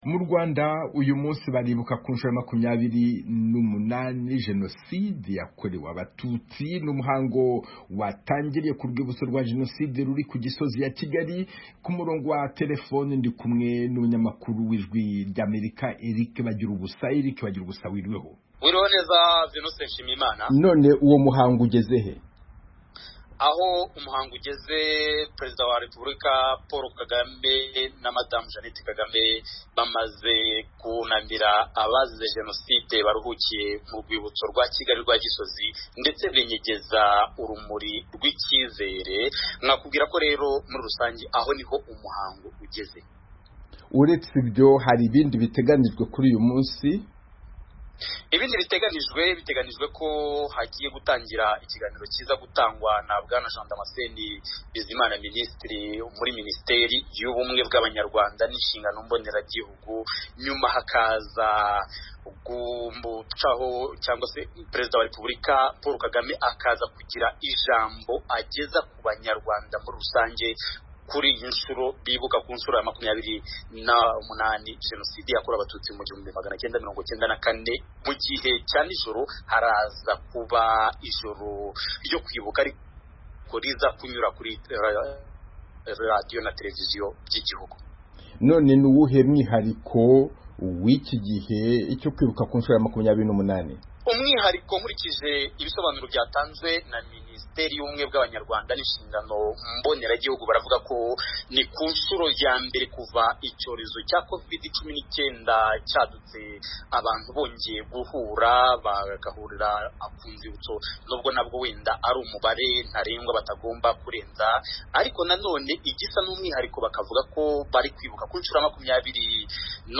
Kunamira Ntaryamira: Ikiganiro na Bwana Sylivestre Ntibantunganya